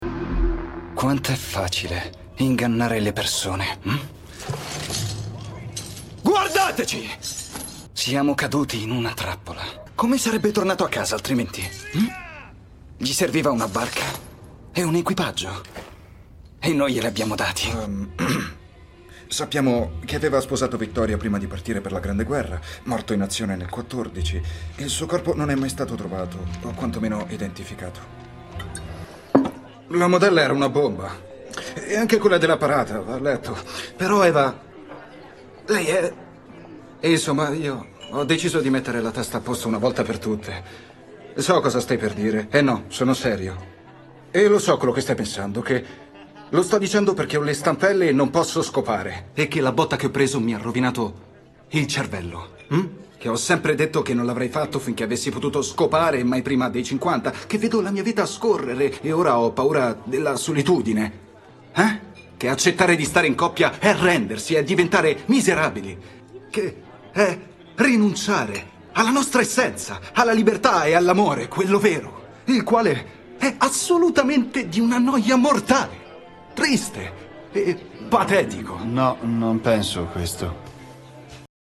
in alcuni estratti dai suoi doppiaggi.